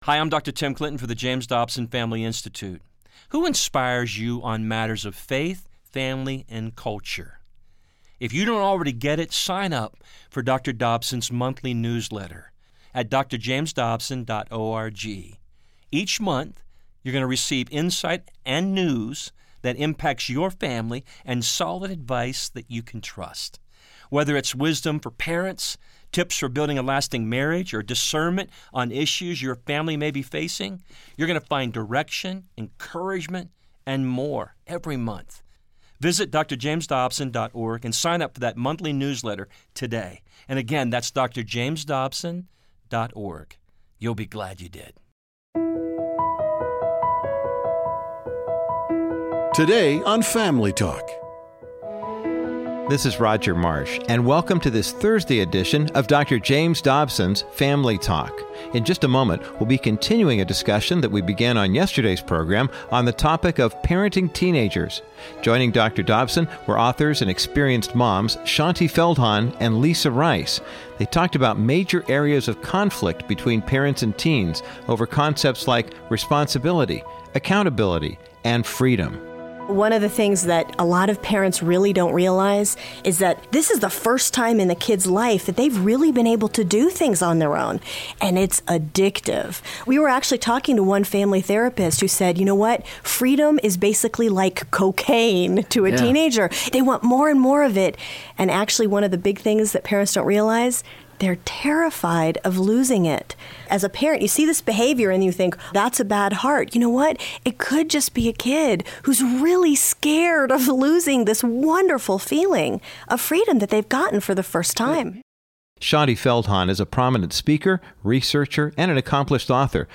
These experienced mothers share their findings on how kids want to be treated, and why parents must carefully answer pivotal worldview questions.